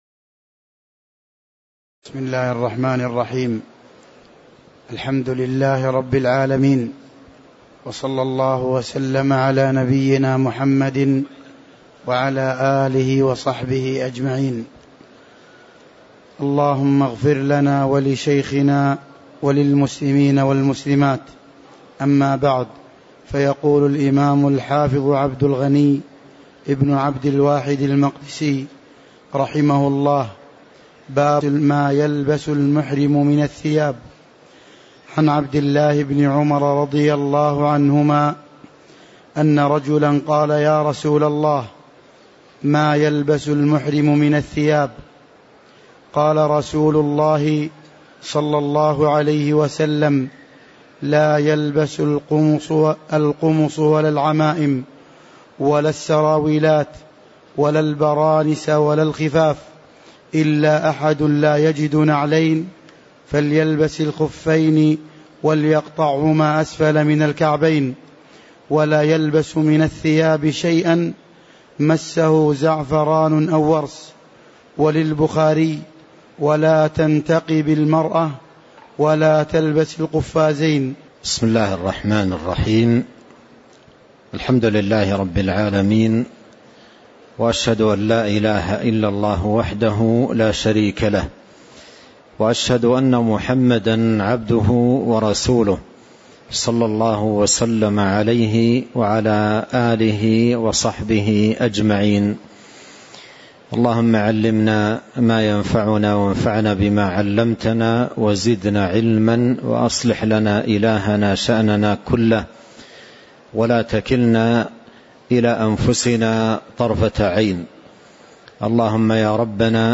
تاريخ النشر ١٠ محرم ١٤٤٤ هـ المكان: المسجد النبوي الشيخ